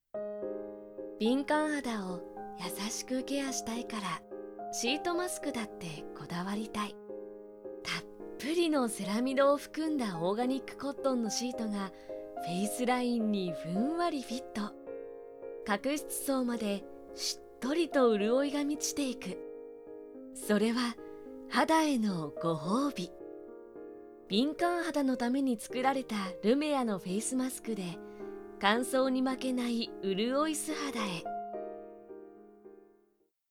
ボイスサンプル
CM